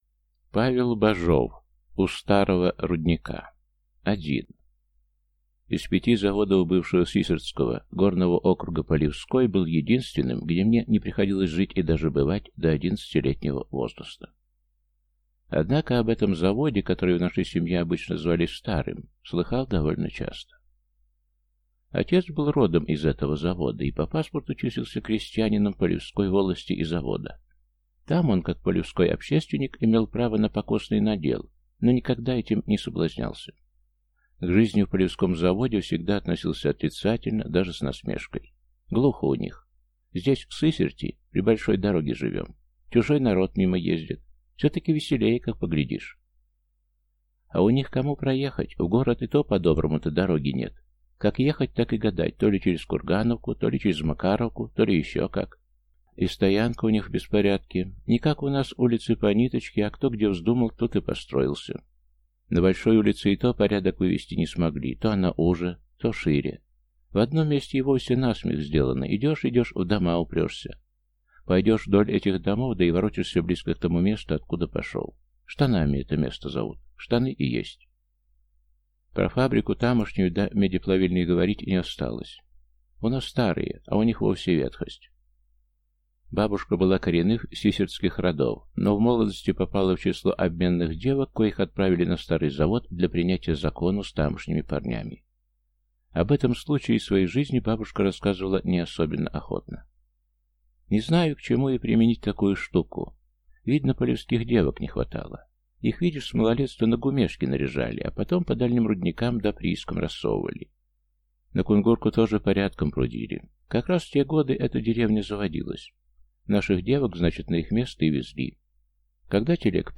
Аудиокнига У старого рудника | Библиотека аудиокниг